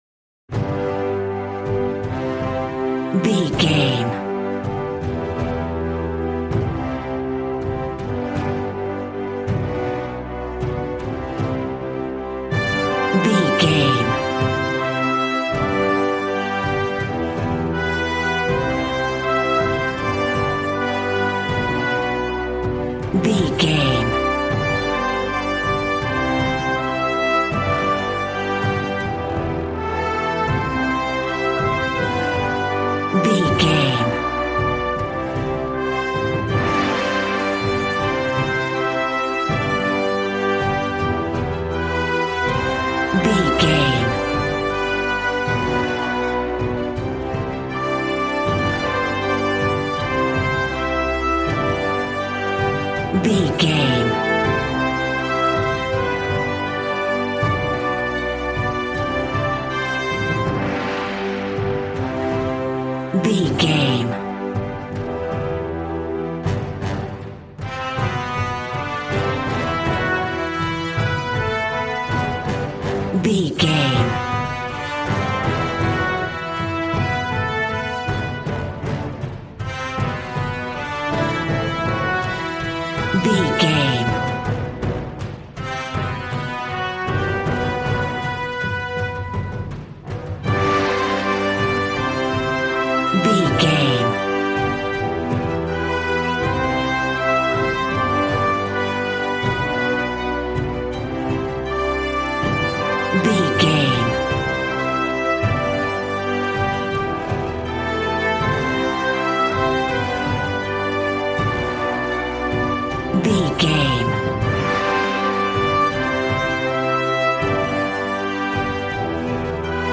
Regal and romantic, a classy piece of classical music.
Ionian/Major
regal
cello
double bass